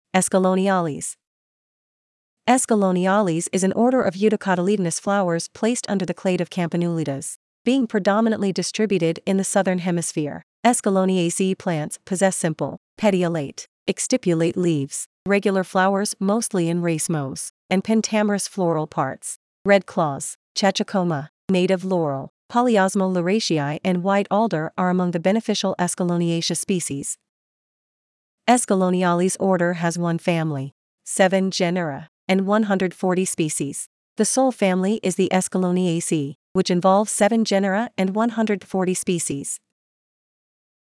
Escalloniales-Pronunciation.mp3